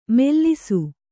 ♪ mellisu